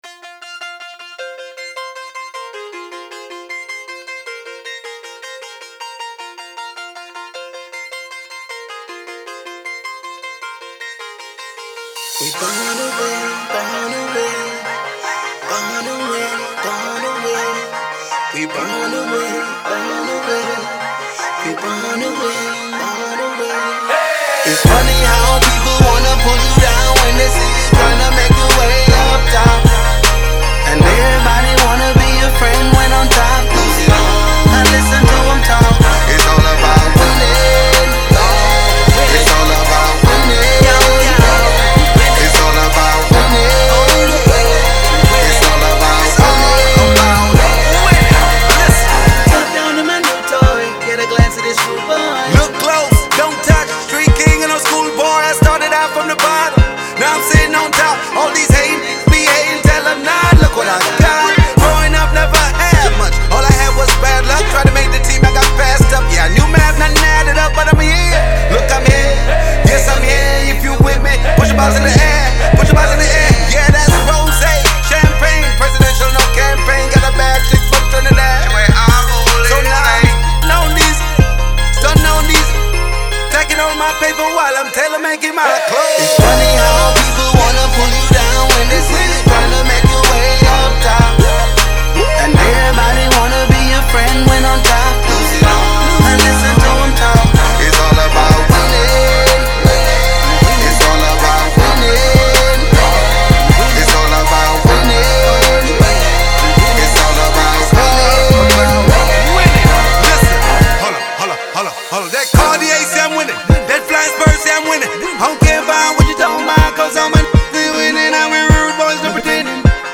is a Trinidad & Tobago-born rapper, singer